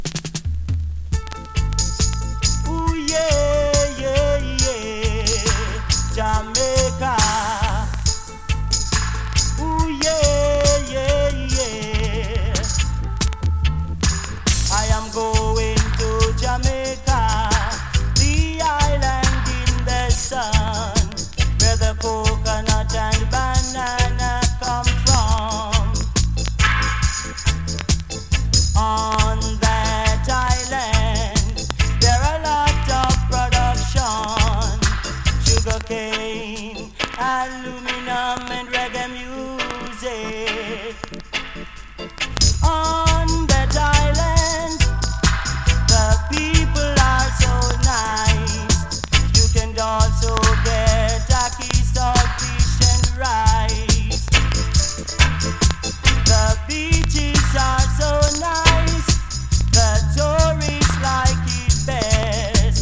scarce and killer vocal